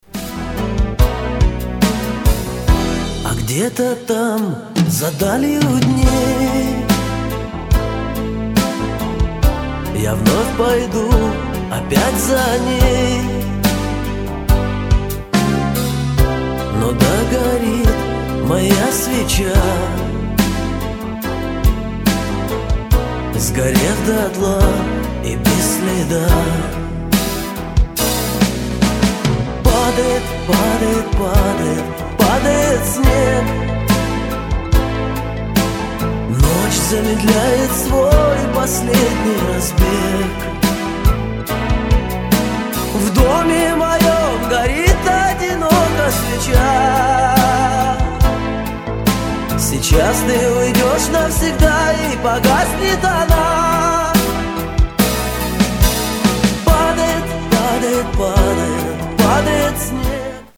Шансон рингтоны